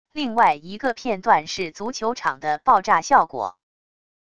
另外一个片段是足球场的爆炸效果wav音频